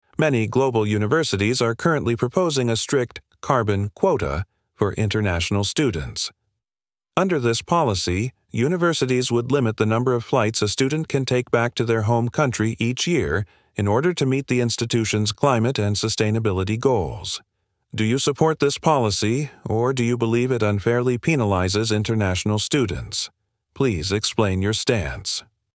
1. Listen to the AI. 2.